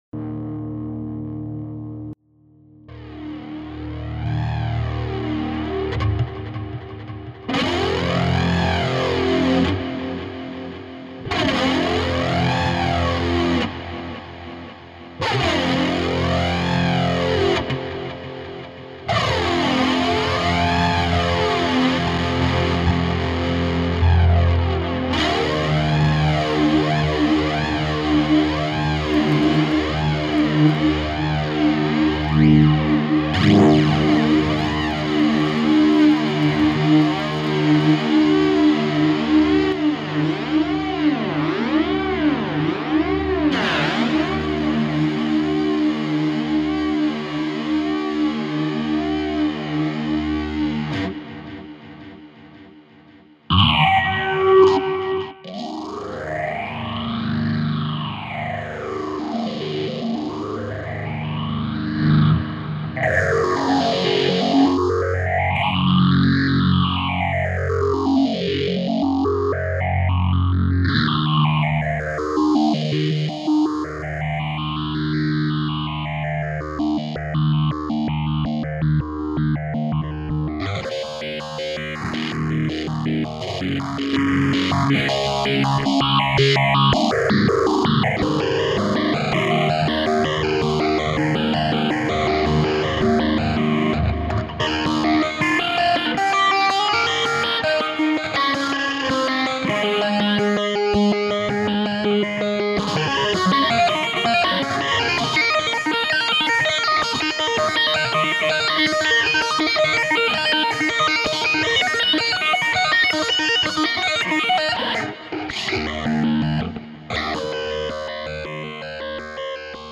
ben ecoute voila un patch avec reverbe delay (un peu abusé mais c'est pour montrer) et un bon vieux flanger
que je modifie pour delirer, et ensuite un step phaser.
flangegt5.mp3